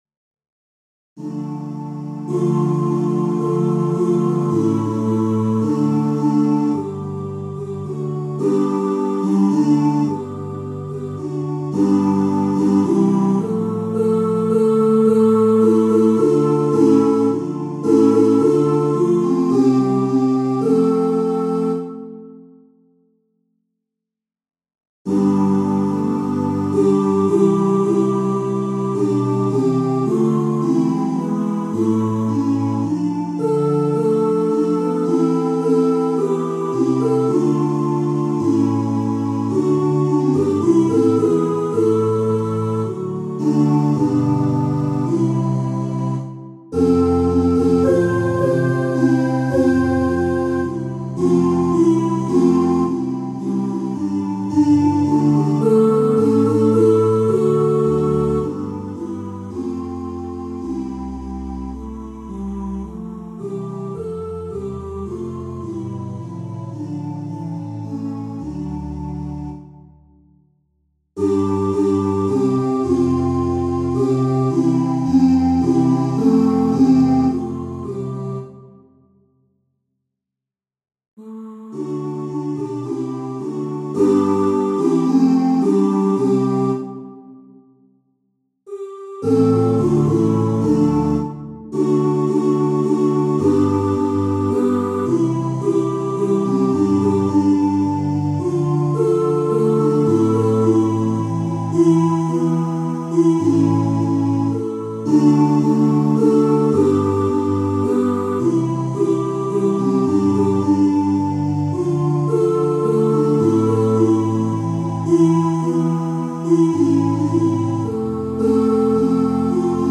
All eight choir parts, no brass instruments:
Tutti (Has the 8 parts sung by the 2 choirs)
omnesgentesmod-2-choirs.mp3